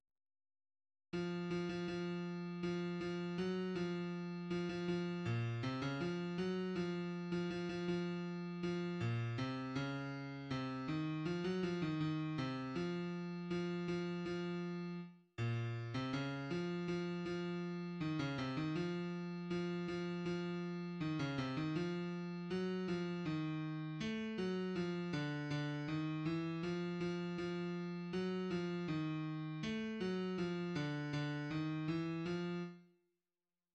{ \clef bass \tempo 4=80 \key des \major \time 2/4 \set Score.currentBarNumber = #1 \bar "" r4 r8 f8 f16 f16 f4 f8 f ges f4 f16 f f8 bes, c16 des f8 ges f8. f16 f16 f16 f4 f8 bes, c des4 c8 ees f16 ges f ees ees8 c f4 f8 f f4 r8 bes,~ bes,16 c des8 f f f4 ees16 des c ees f4 f8 f f4 ees16 des c ees f4 ges8 f ees4 a8 ges f d8 d ees e f f f4 ges8 f ees4 a8 ges f d8 d ees e f r r4 } \addlyrics {\set fontSize = #-2 doggy doogy } \midi{}